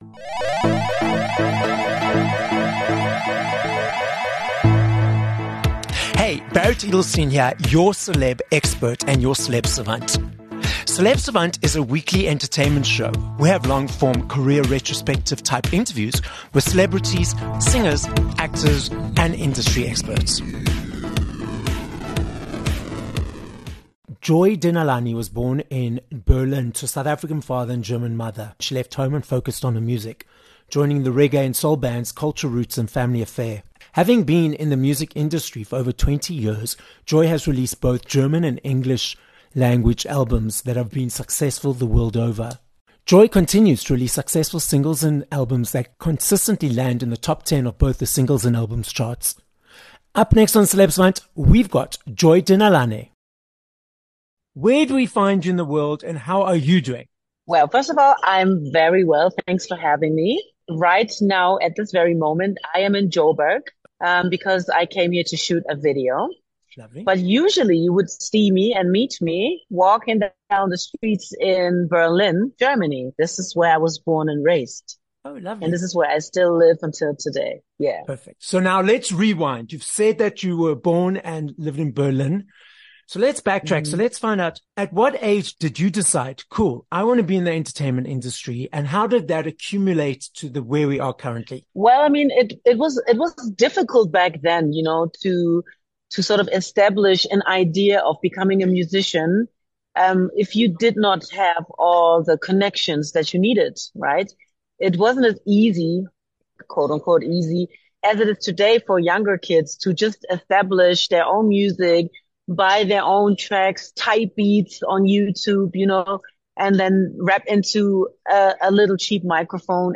21 Sep Interview with Joy Denalane